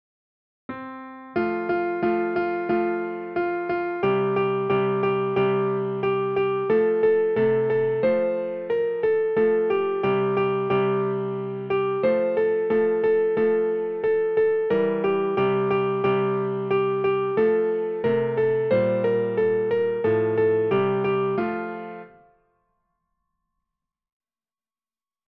is a traditional Nursery Rhyme.
for piano